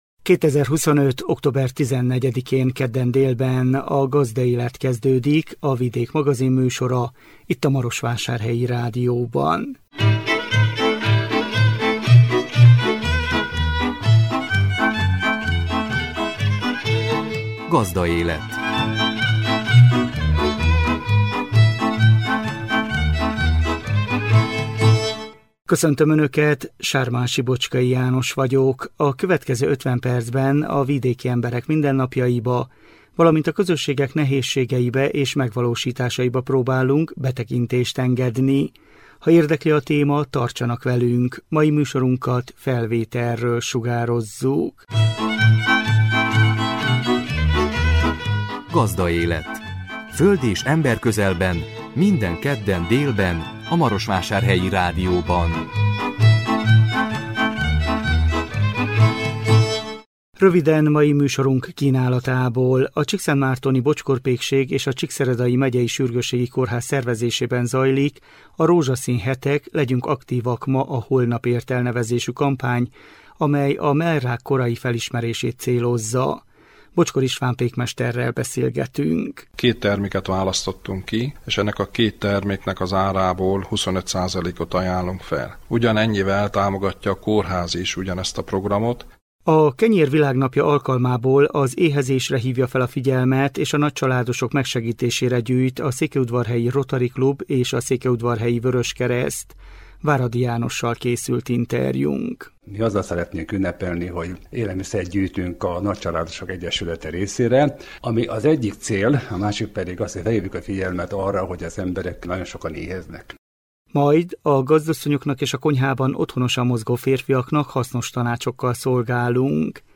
A hangos meghívót Fekete Örs polgármester nyújtja át. A hétvégén 11. alkalommal szervezik meg a Gazdanapot Kápolnásfaluban, Homoródlokán. Benedek László polgármester avat be a részletekbe.